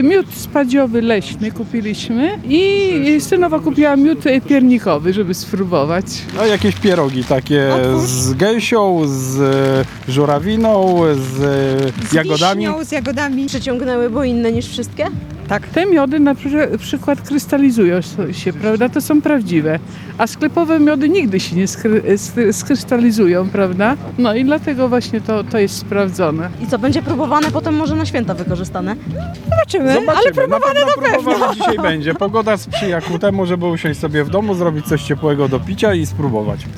– Kupiliśmy miód spadziowy leśny, a synowa miód piernikowy, żeby spróbować – mówią uczestnicy.